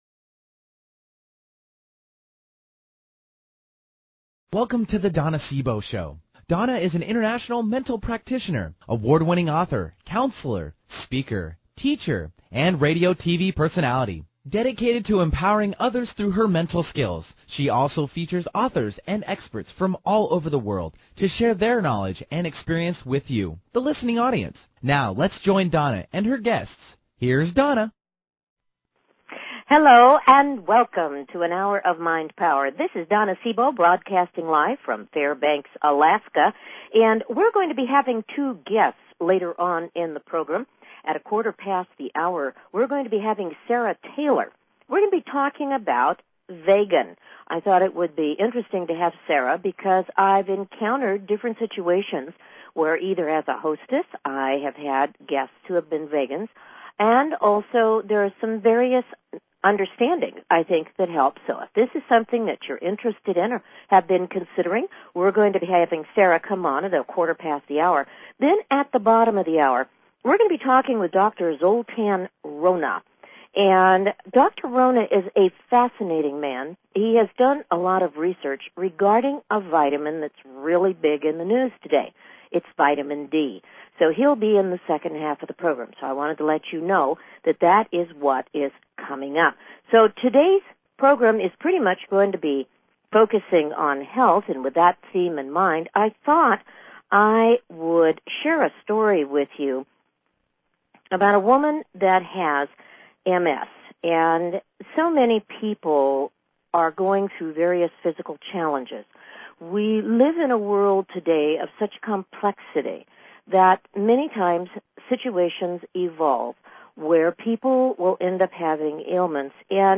Talk Show Episode
April 13, 2010 - Two guests this hour. 1